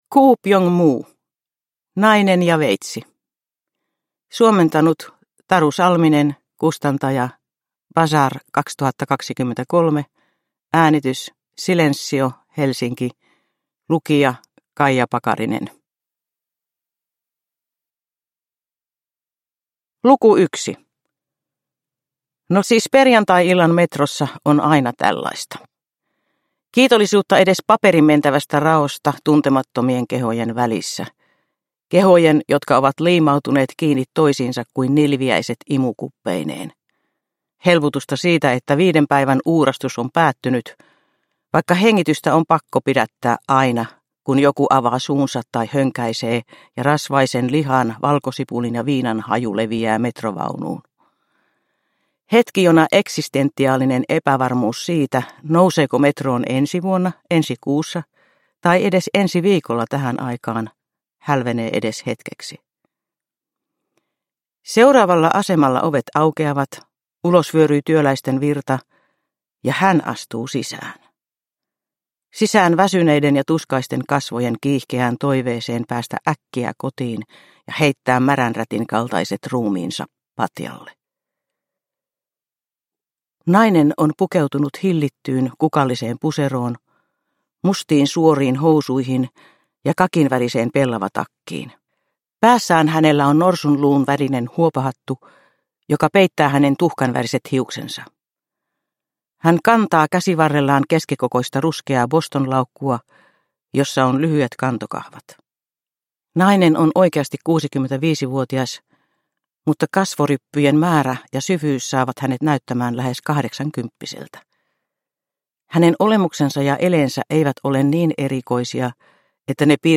Nainen ja veitsi – Ljudbok – Laddas ner